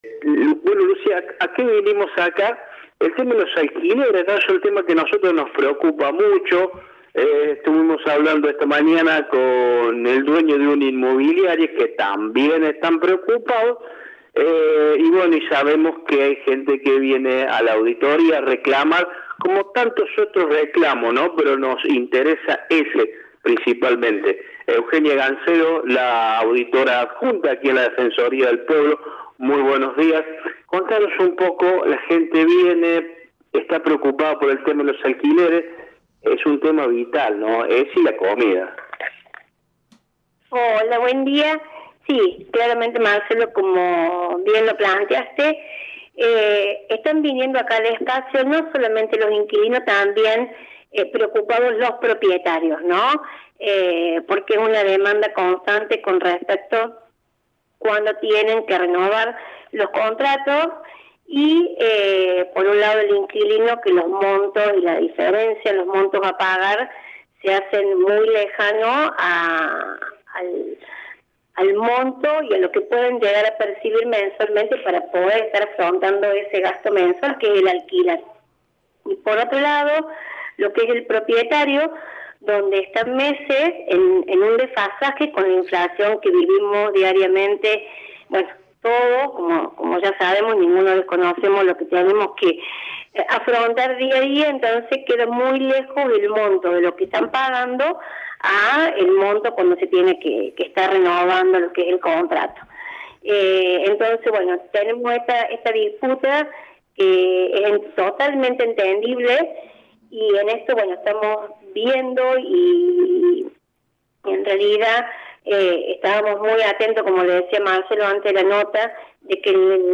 Eugenia Gancedo, titular del organismo, en comunicación con nuestro medio acercó algunos consejos al respecto.